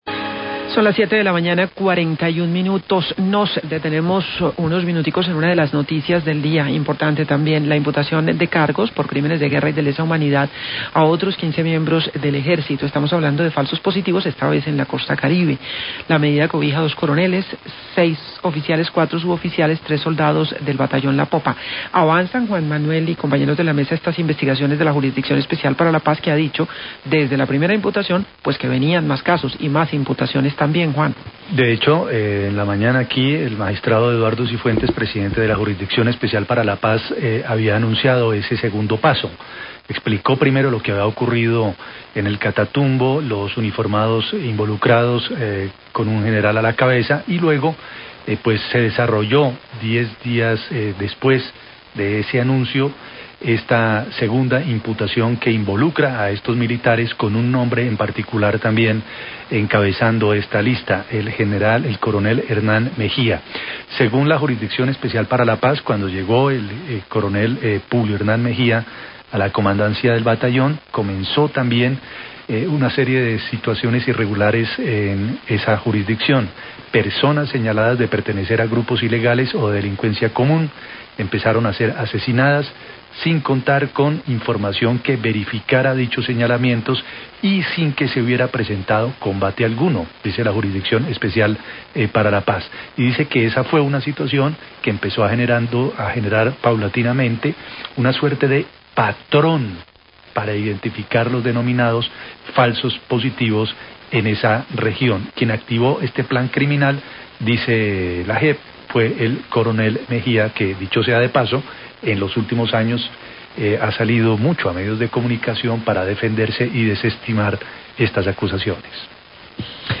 Periodistas del noticiero analizan el desempeño de JEP en el macrocaso de falsos positivos
Los periodostas de la mesa analizan la imputación de cargos por falsos positivos a 15 militares del Batallón La Popa y la entrevista con el presidente de la JEP, magistrado Eduardo Cifuentes. También analizan desempeño de la JEP en el macrocaso de faslos positivos.